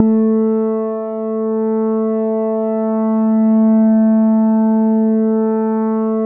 AN2 ANA PAD.wav